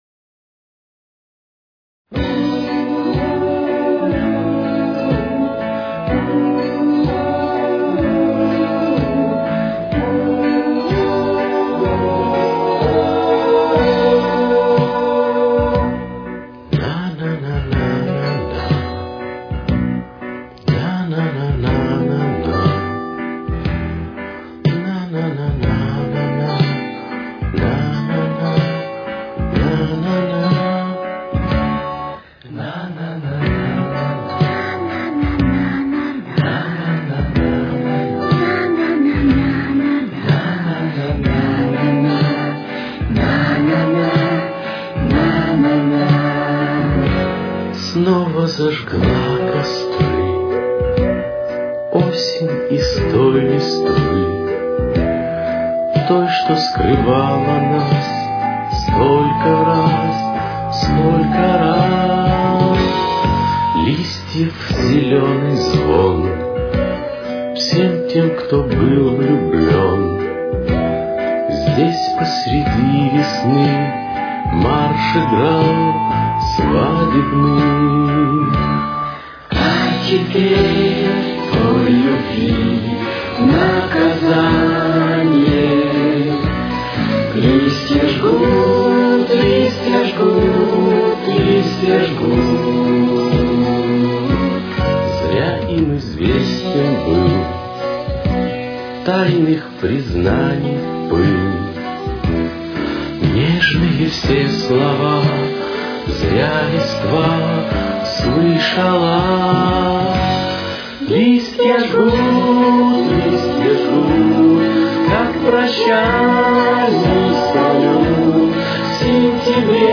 Тональность: Си минор. Темп: 124.